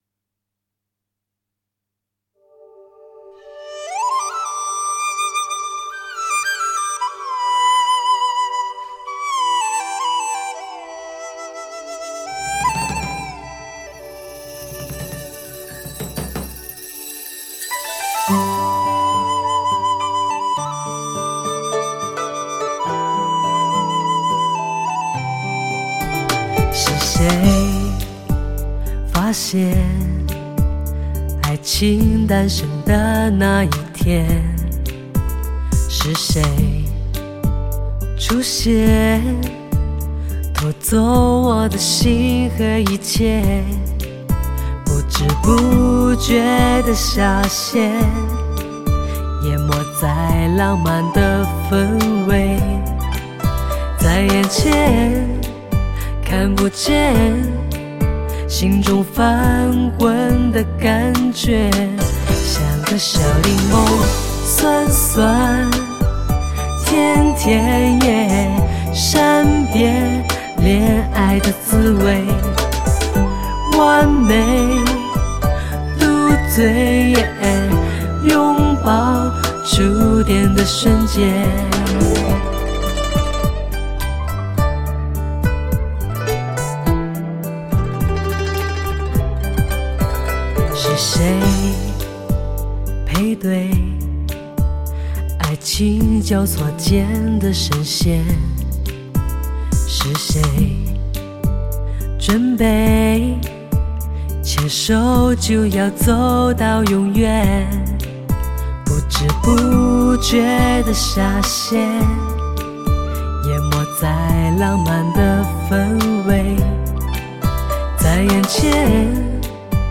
不可多得的发烧天籁女声，发烧够味道，发烧音响族圣物。